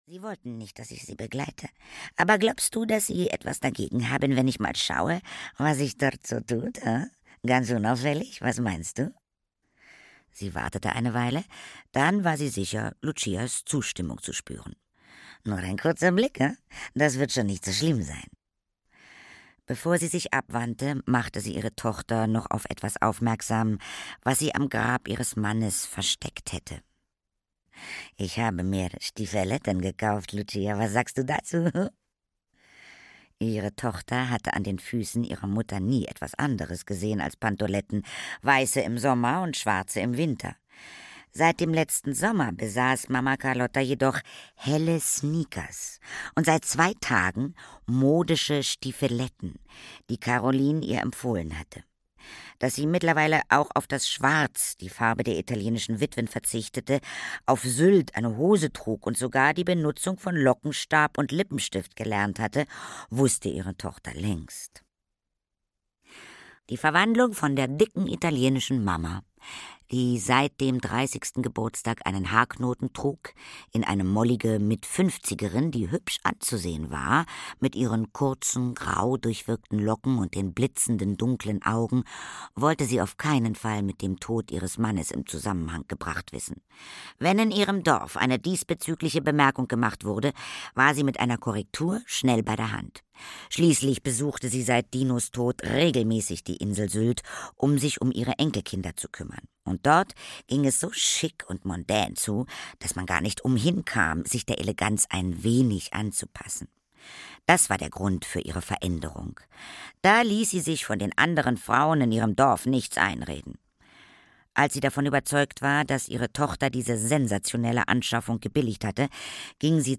Inselzirkus (Mamma Carlotta 5) - Gisa Pauly - Hörbuch